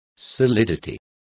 Complete with pronunciation of the translation of solidity.